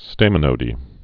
(stāmə-nōdē, stămə-)